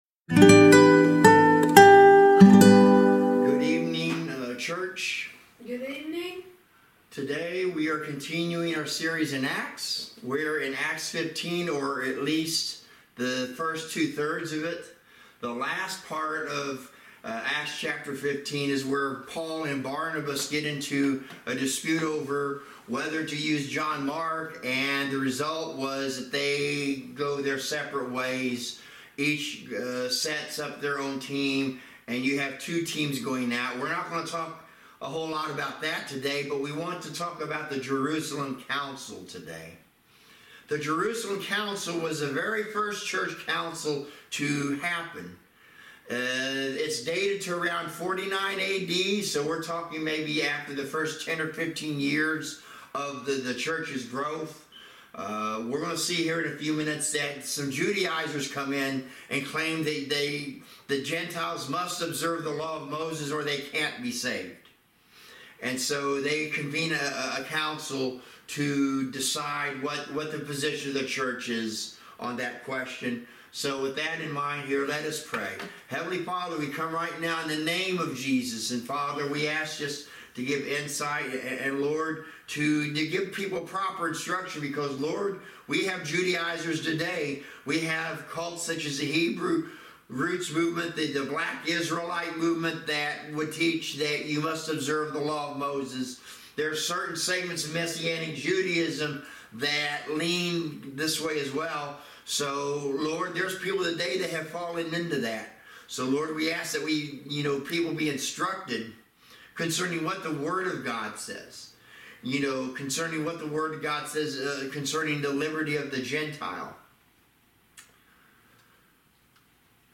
Service Type: Thirsty Thursday Midweek Teaching